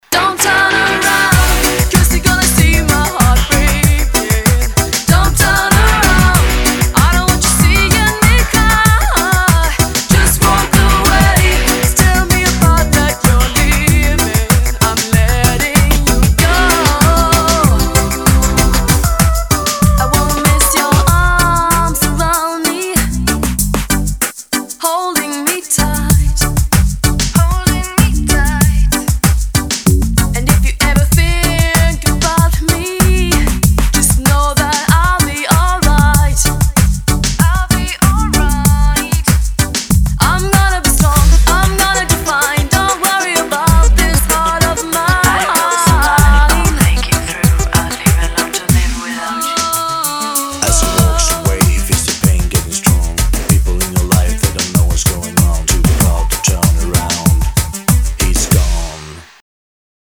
• Качество: 320, Stereo
поп
зажигательные
90-е
красивый женский голос
танцевальные